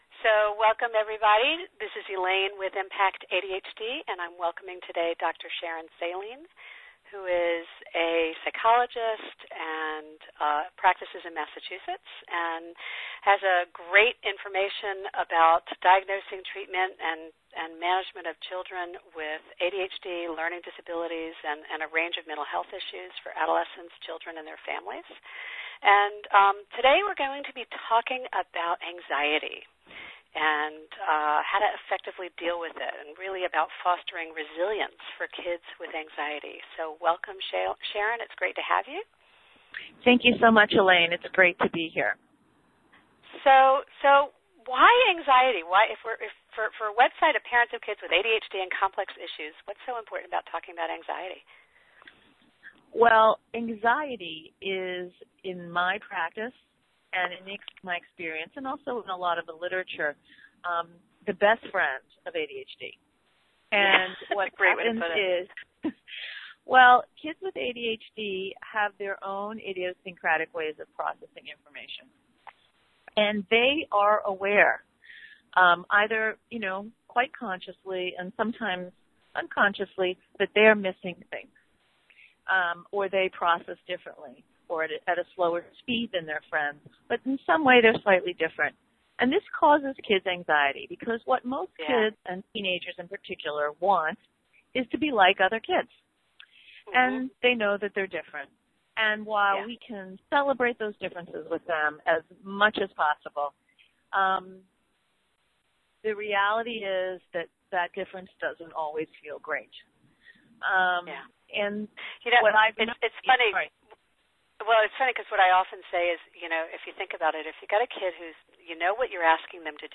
Fostering Resilience for Kids with Anxiety an Interview